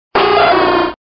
Archivo:Grito de Murkrow.ogg